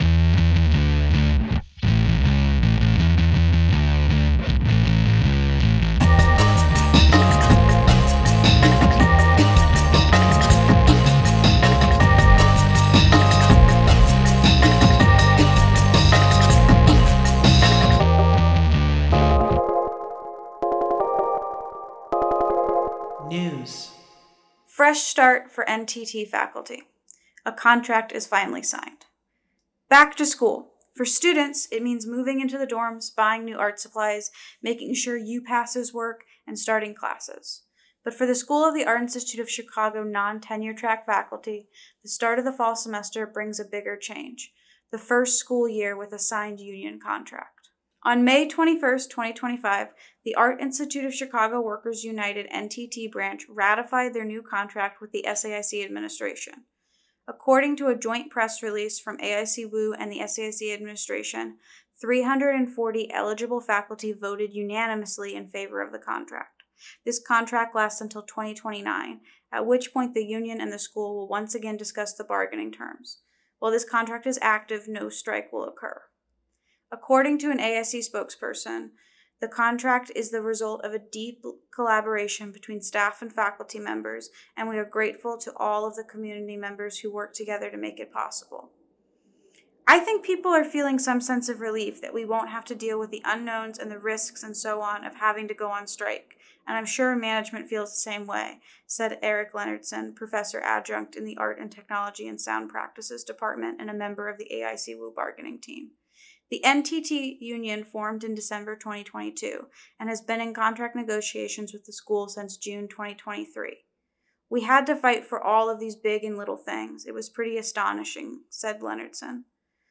The text of this article was read aloud and recorded for your greater accessibility and viewing pleasure: